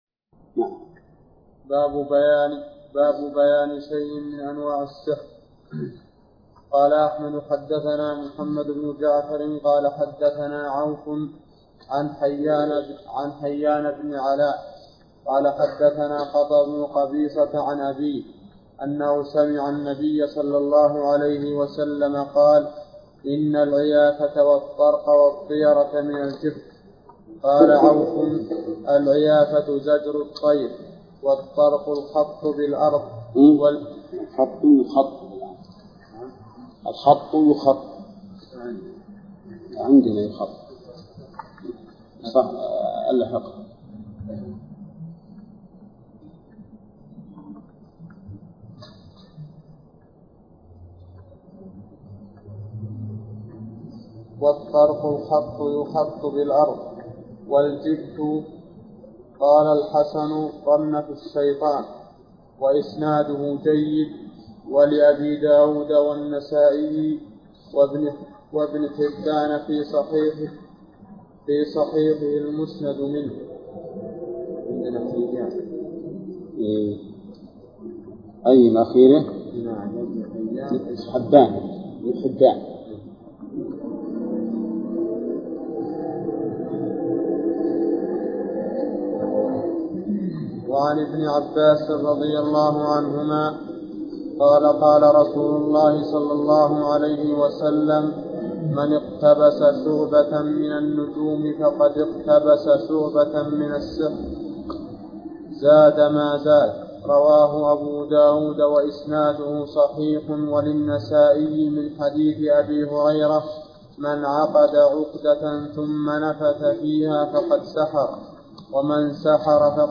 درس (25) : من صفحة: (513)، قوله: (باب: بيان شيء من أنواع السحر).